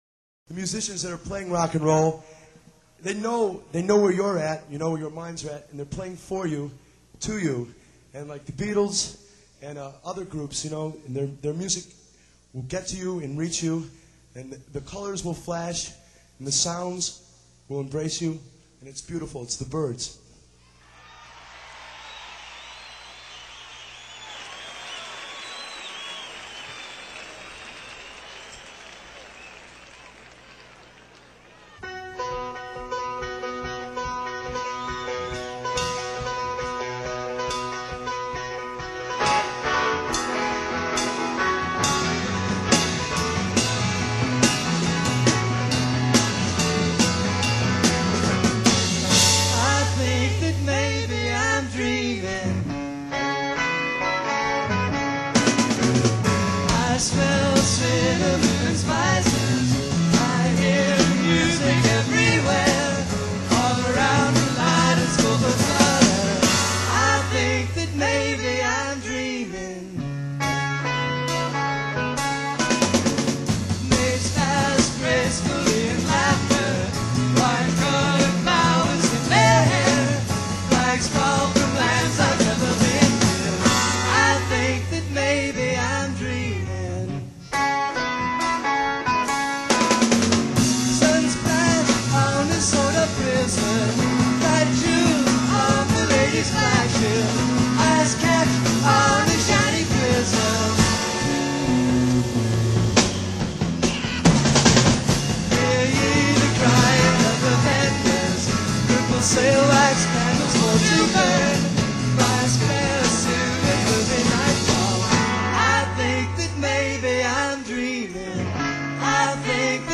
Band soundboard
playing the Monterey Pop Festival in 1967